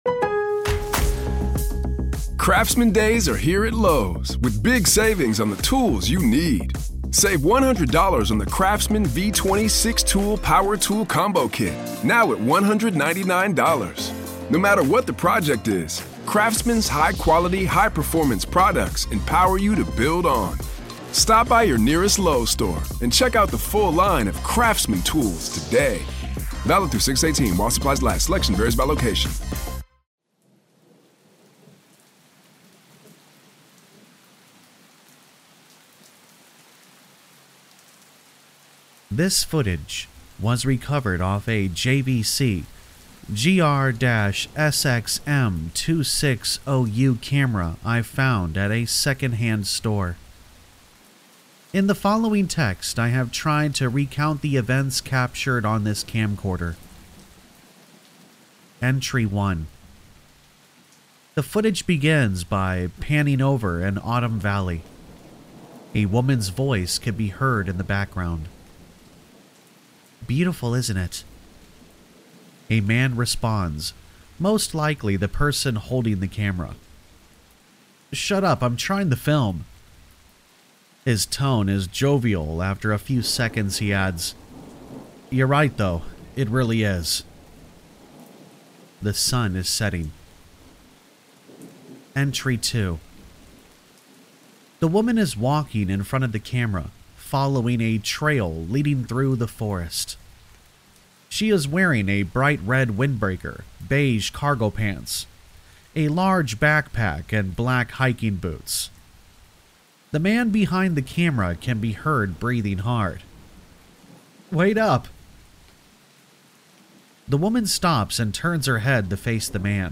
With spine-tingling sound bites and compelling visuals, we aim to unravel the mystery and keep you on the edge of your seat. WARNING: The camcorder audio featured in this video may be disturbing to some viewers.